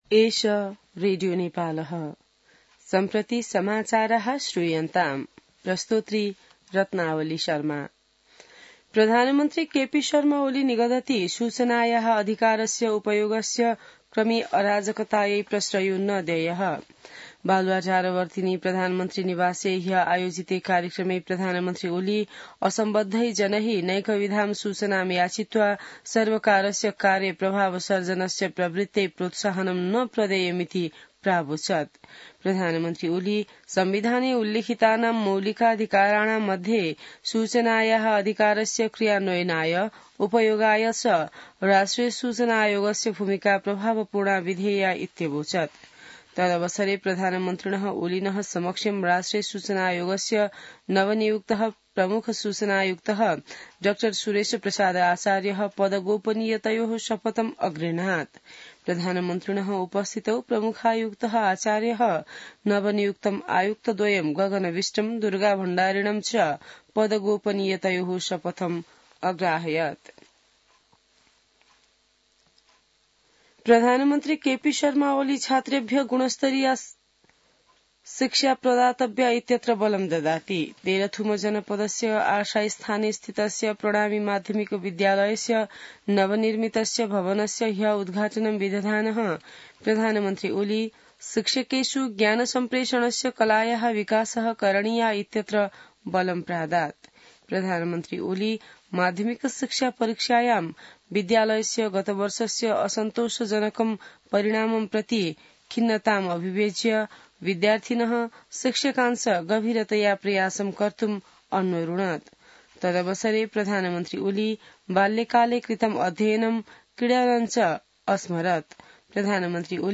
संस्कृत समाचार : २८ मंसिर , २०८१